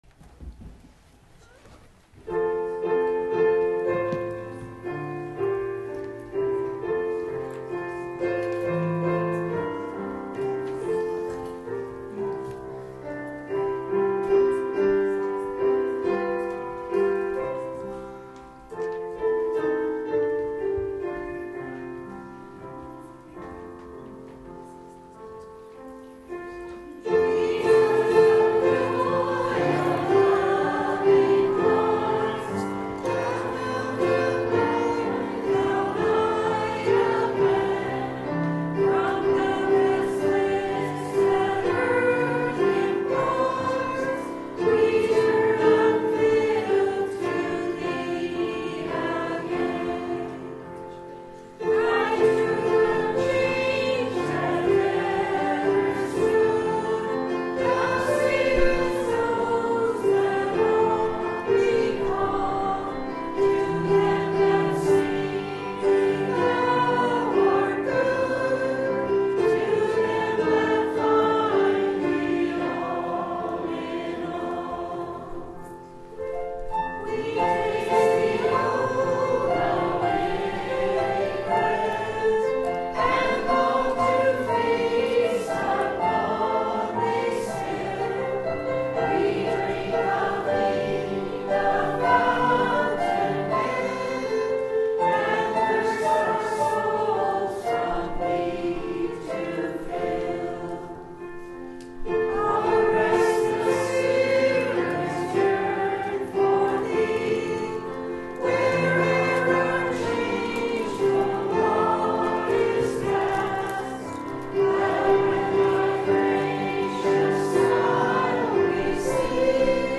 Communion Sunday